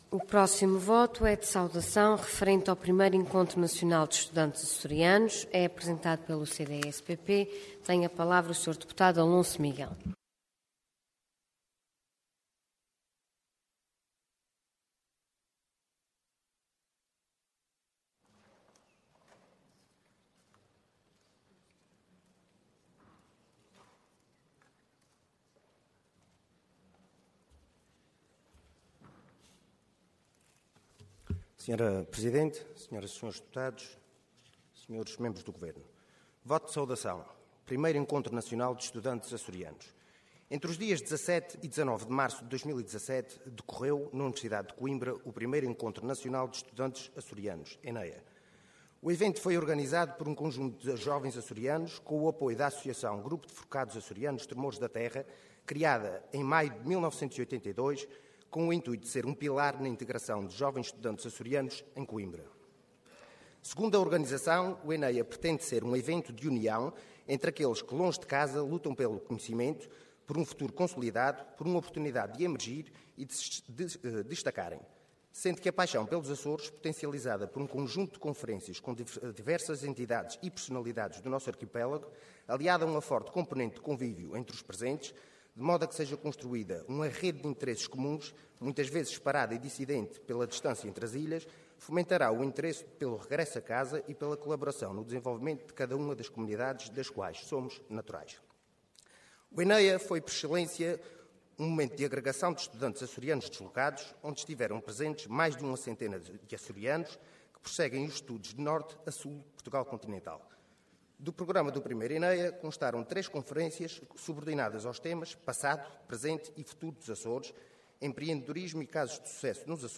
Intervenção Voto de Saudação Orador Alonso Miguel Cargo Deputado Entidade CDS-PP